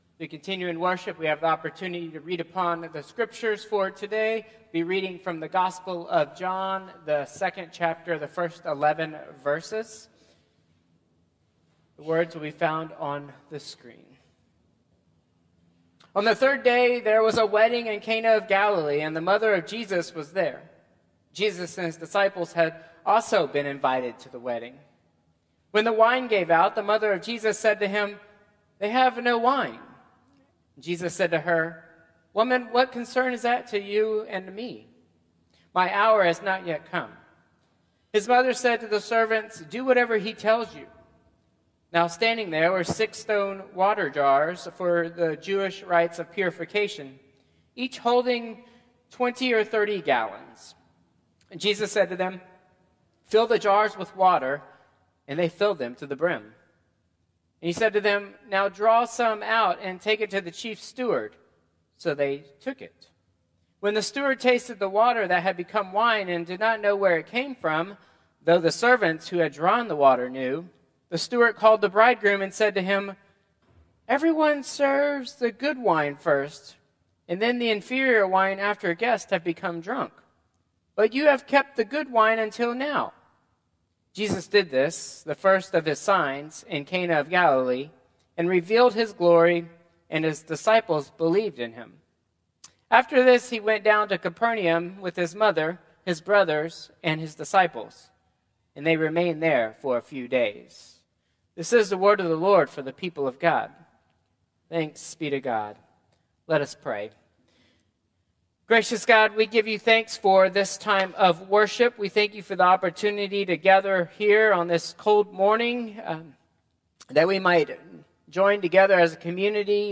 Traditional Service 1/19/2025